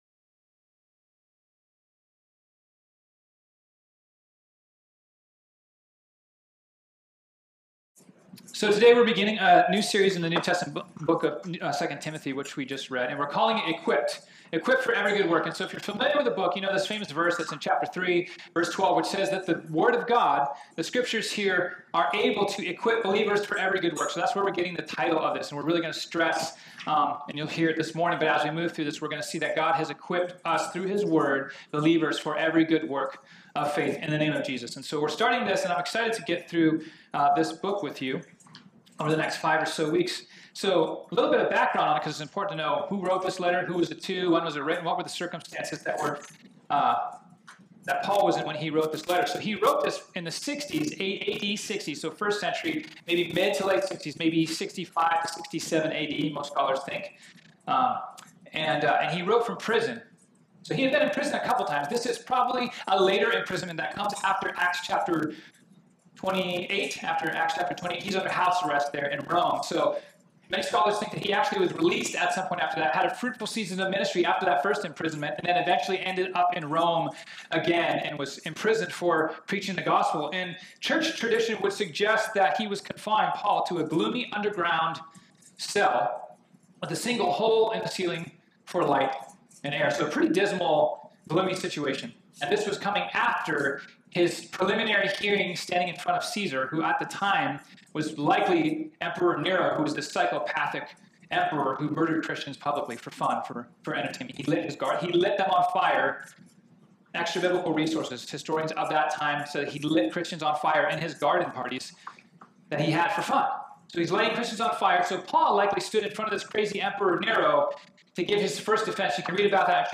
This sermon was originally preached on Sunday, August 4, 2019.